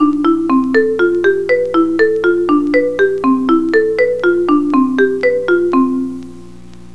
Now we are going to use ALEATORIC to construct a musical example, creating a melody with pitches chosen randomly from a fixed set of possibilities.